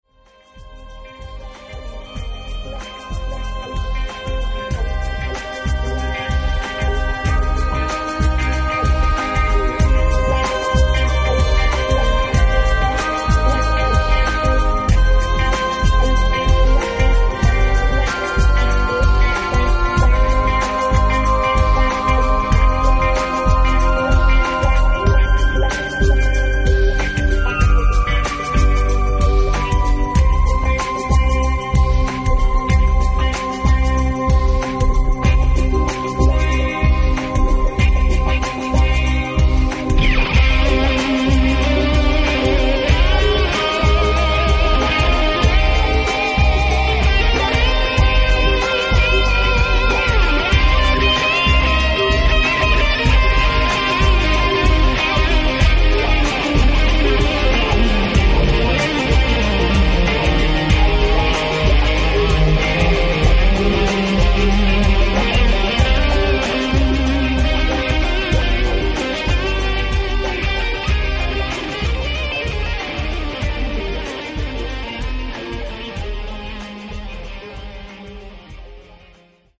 (Magyar népdal)    5'40"